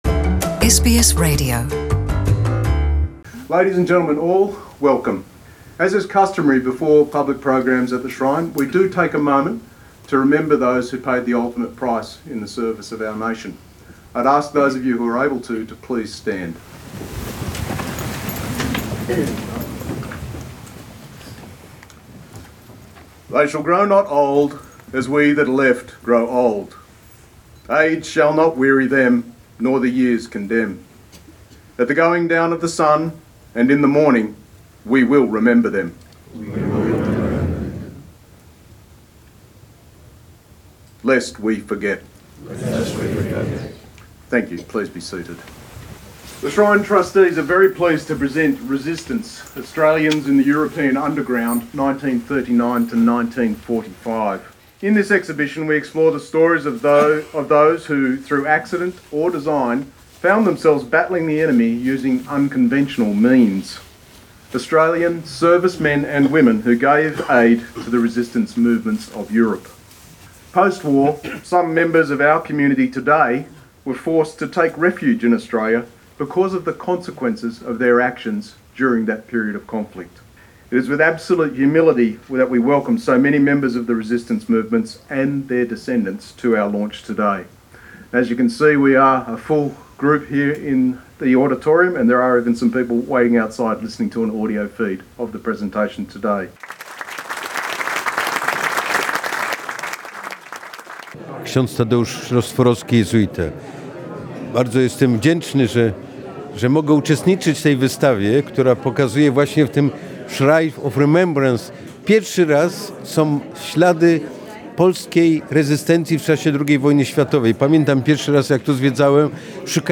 Poles where very welcomed at the exhibition launch at Melbourne Shrine of Remembrance. SBS Radio Polish Program was also present at the opening of the exhibition.